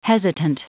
Prononciation du mot hesitant en anglais (fichier audio)
Prononciation du mot : hesitant